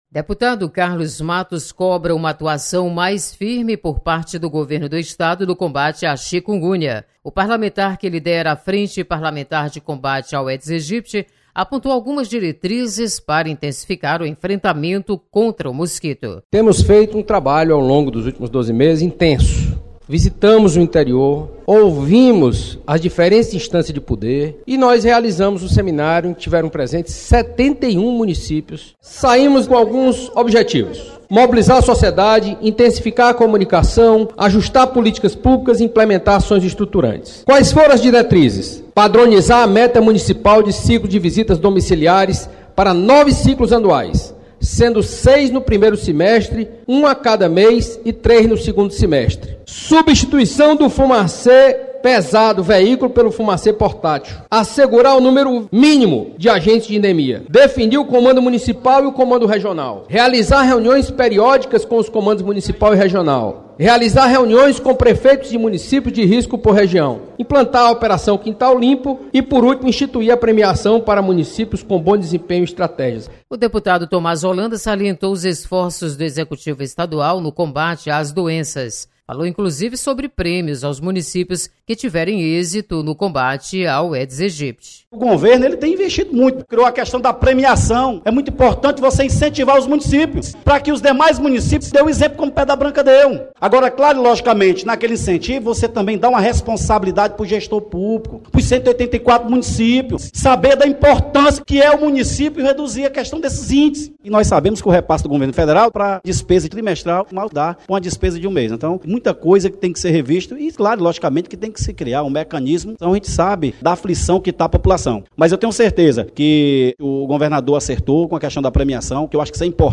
Deputado cobra mais ação contra o Aedes aegypti. Repórter